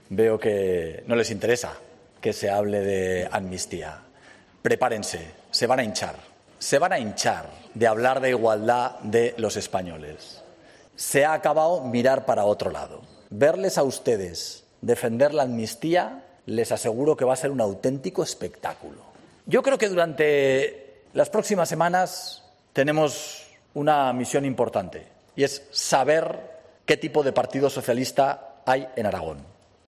Declaraciones del presidente Azcón en el primer pleno de esta legislatura en las Cortes de Aragón.